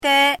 怎么读
[ dē ]
de1.mp3